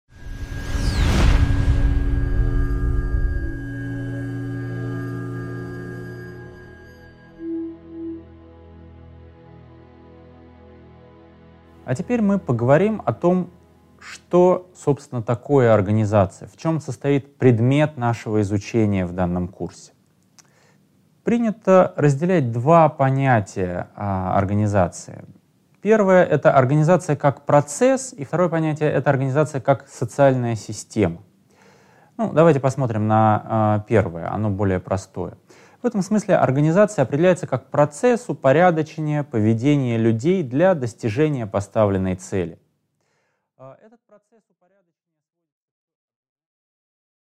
Аудиокнига 1.2. Понятие организации | Библиотека аудиокниг